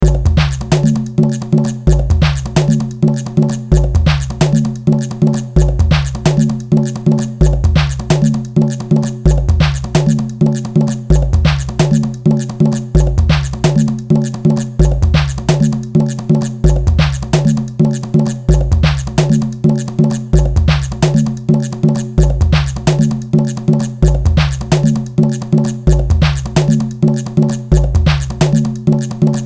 Filed under: Instrumental | Comments (2)